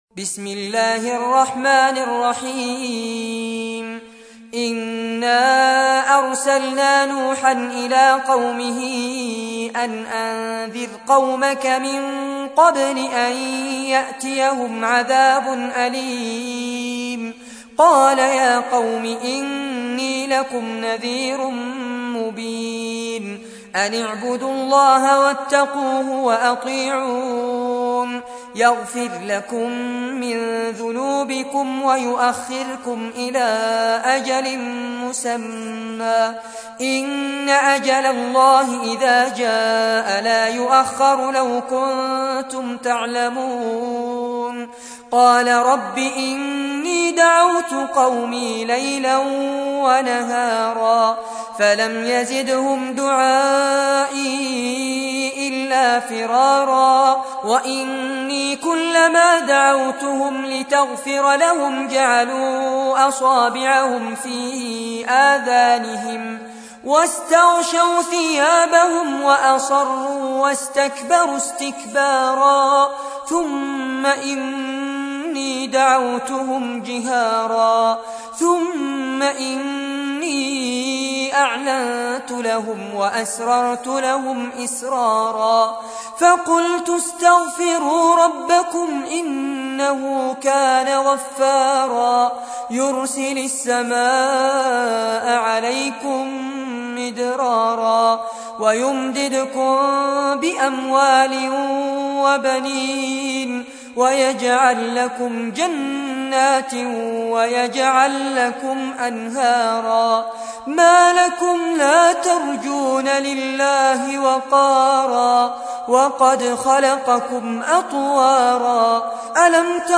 تحميل : 71. سورة نوح / القارئ فارس عباد / القرآن الكريم / موقع يا حسين